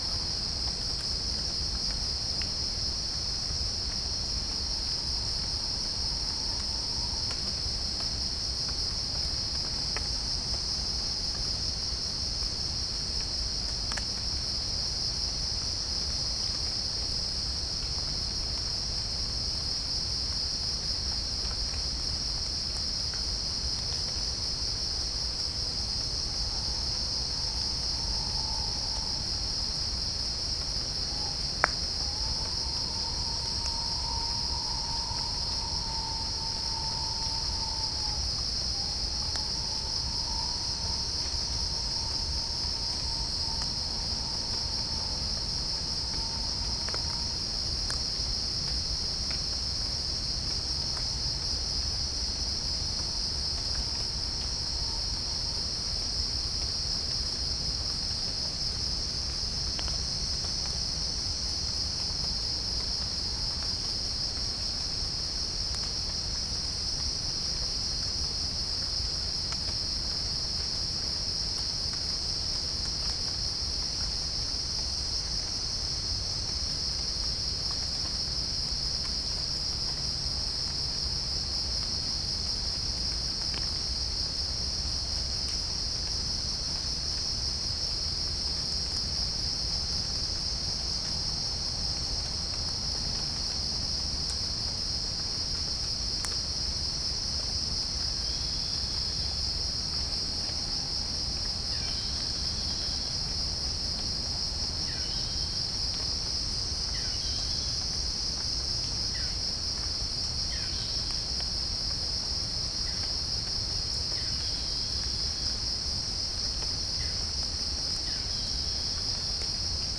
Geopelia striata
Pycnonotus goiavier
Halcyon smyrnensis
Orthotomus ruficeps
Dicaeum trigonostigma